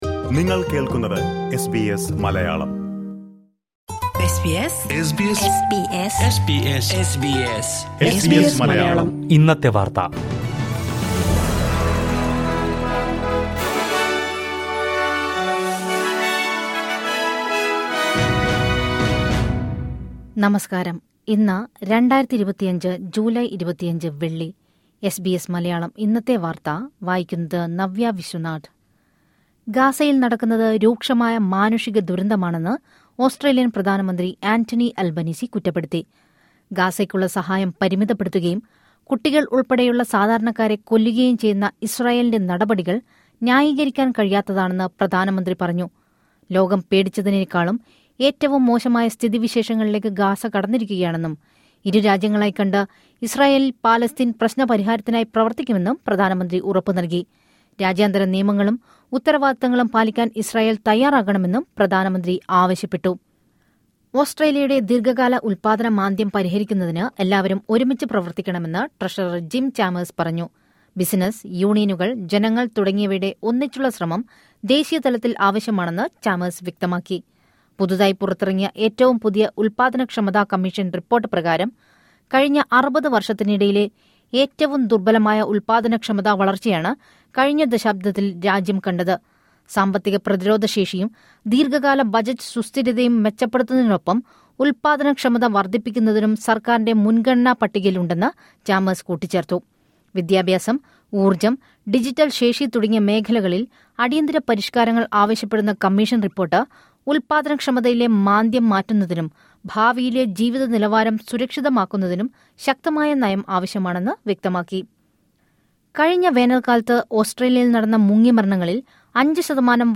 2025 ജൂലൈ 25ലെ ഓസ്‌ട്രേലിയയിലെ ഏറ്റവും പ്രധാന വാര്‍ത്തകള്‍ കേള്‍ക്കാം...